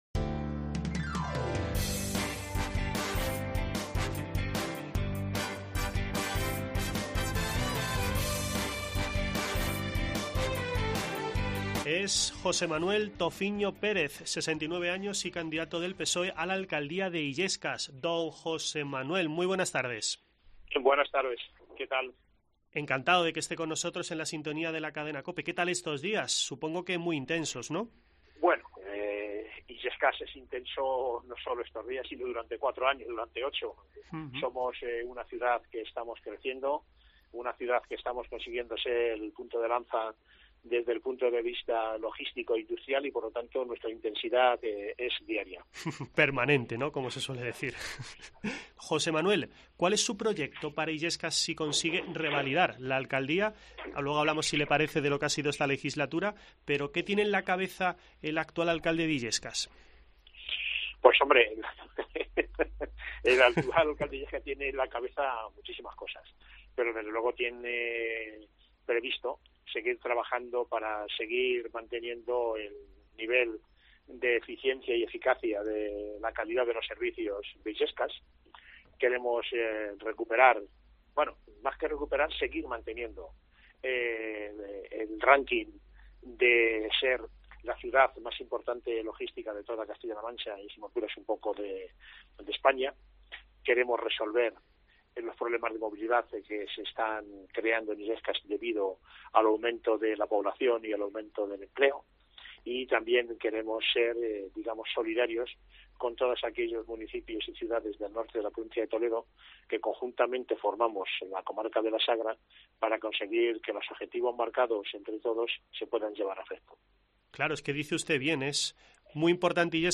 Escucha en 'Herrera en COPE' la entrevista con el candidato del PSOE en la localidad toledana de Illescas en las elecciones municipales del próximo domingo, 28 de mayo